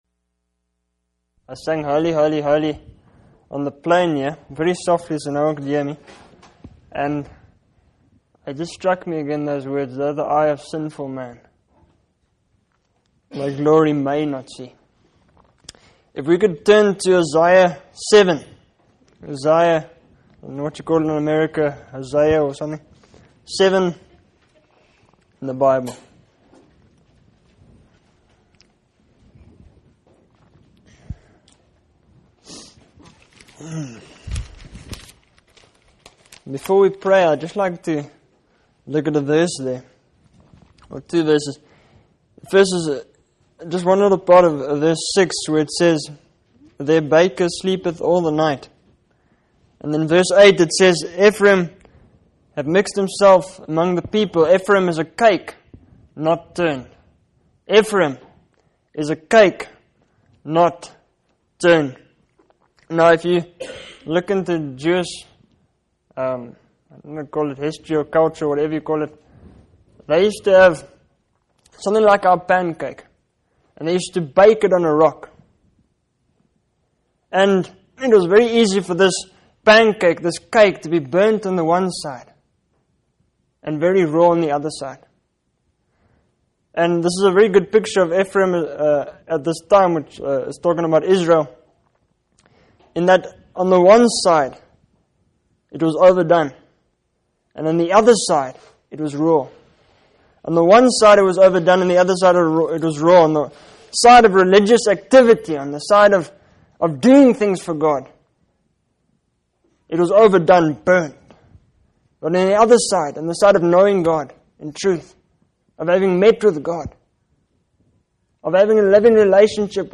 In this sermon, the preacher emphasizes the importance of repentance and turning away from sin.